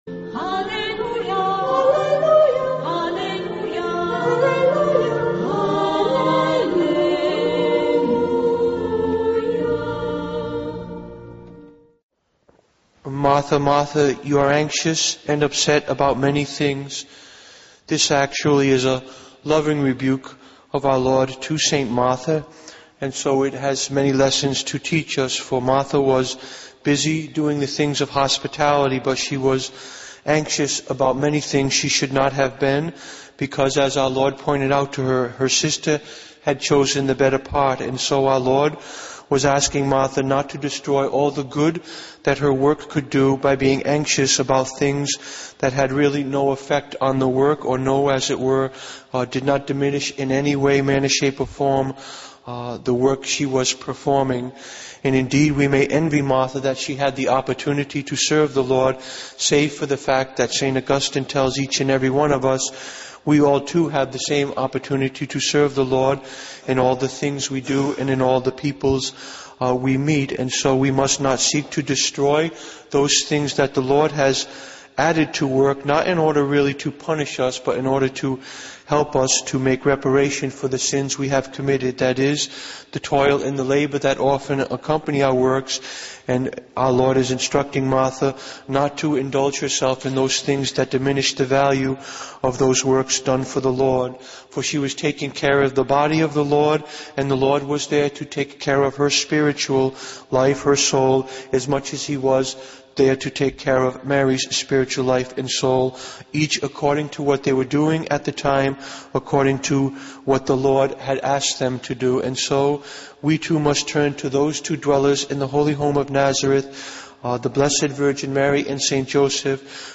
Homilies #186 - St Denis and Companions(5min) >>> Play ?